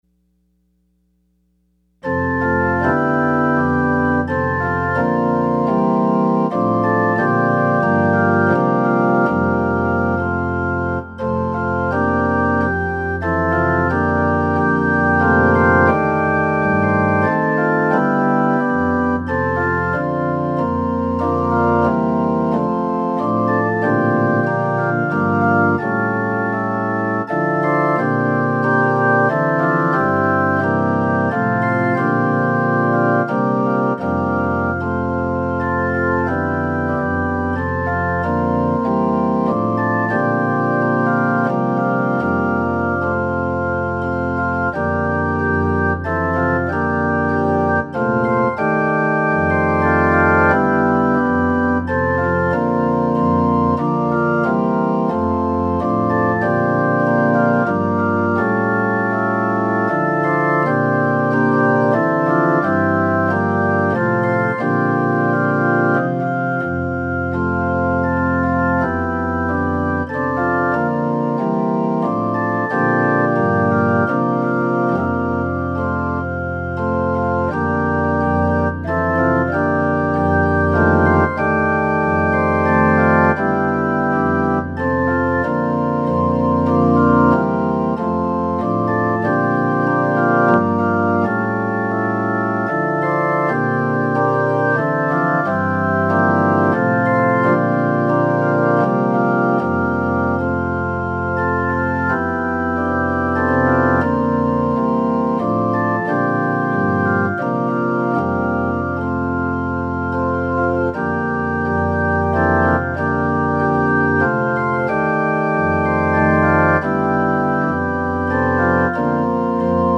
Closing Hymn – Praise the Spirit in creation #507